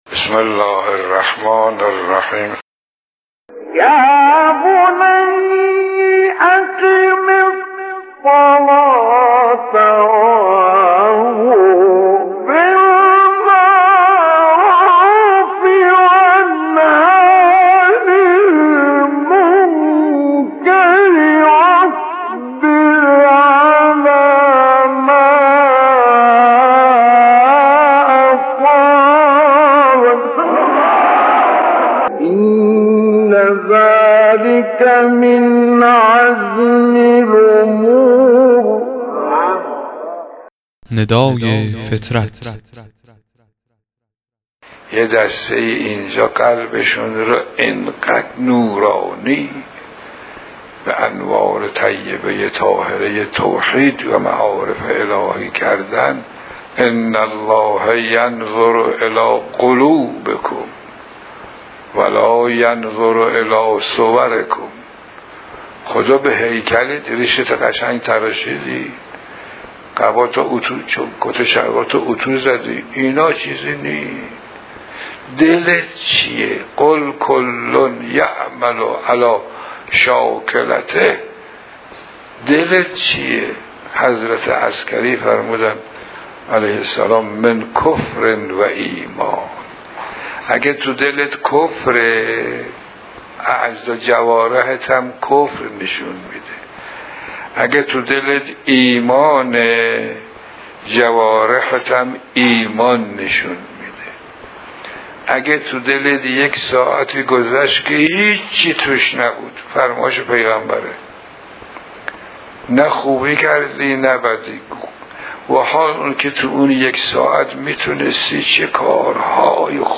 مواعظ گوناگون و مطالب و نکاتی که در قالب صوت هستند و مختصر و مفید می‌باشند و پند و اندرز می‌دهند، در این بخش مطرح می‌شود.